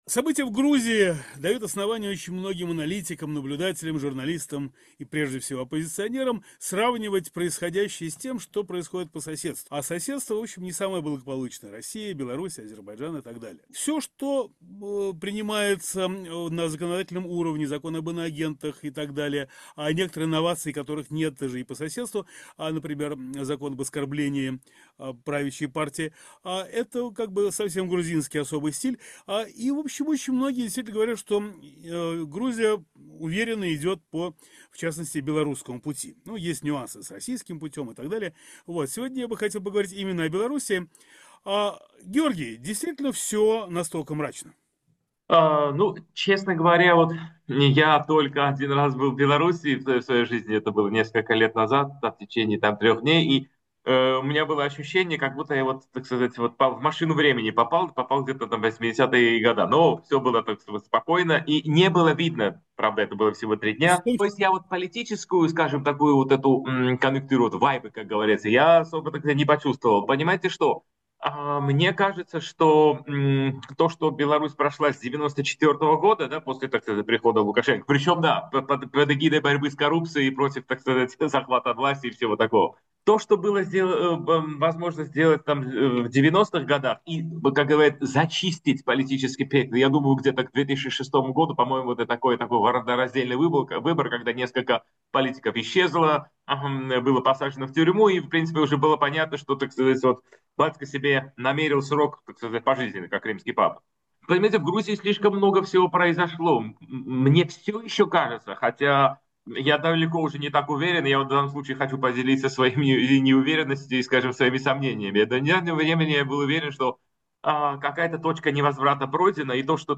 Рубрика Некруглый стол, разговор с экспертами на самые актуальные темы